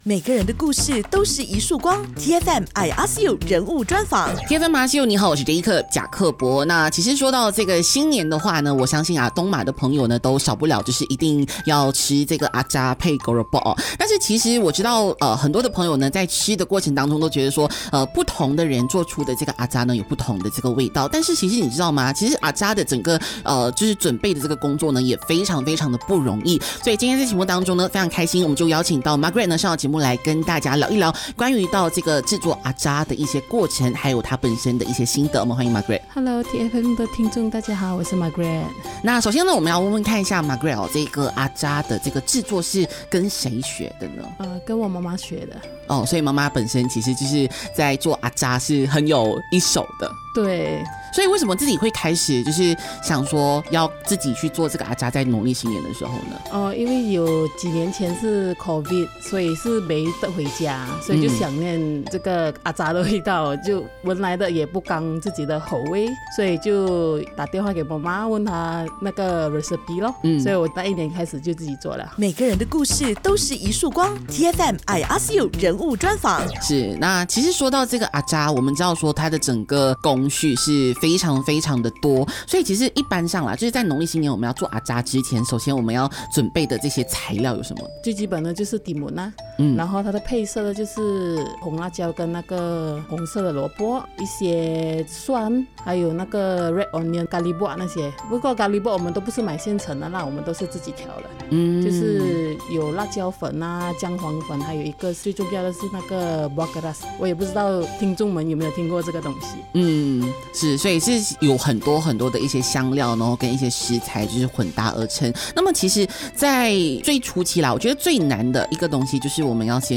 人物专访